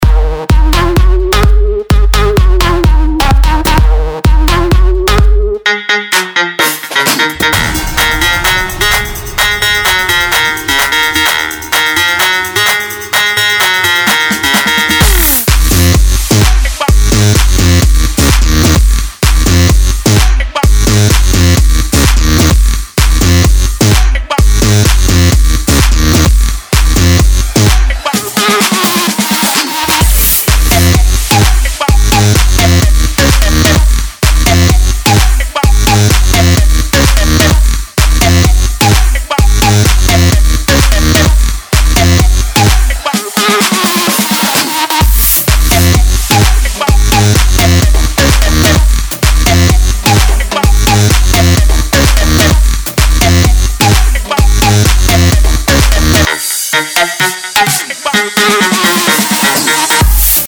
ритмичные
громкие
dance
Electronic
EDM
Bass
Запоминающаяся миксованная мелодия